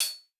TC2 Live Hihat7.wav